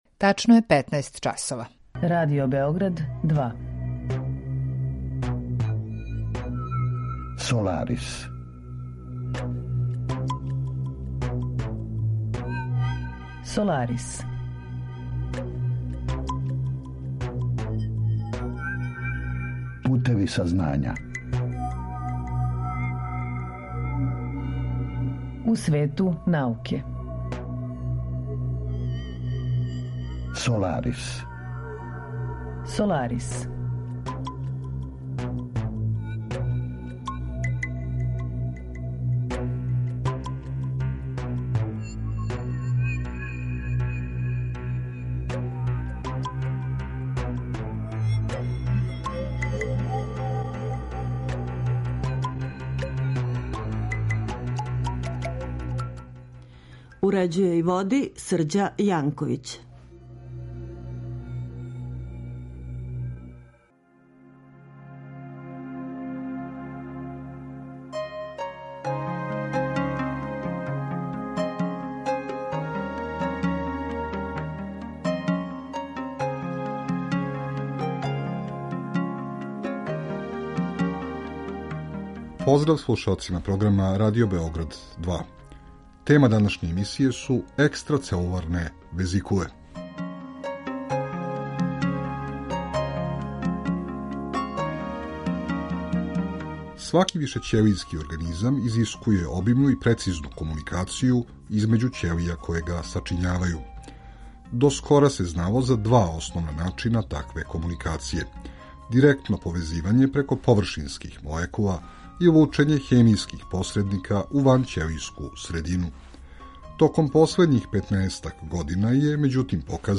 Саговорници у емисији обухватају научнике који се баве истраживањима из различитих области, од носилаца врхунских резултата и признања до оних који се налазе на почетку свог научног трагања, али и припаднике разноврсних професија који су у прилици да понуде релевантна мишљења о одговарајућим аспектима научних подухвата и науке у целини.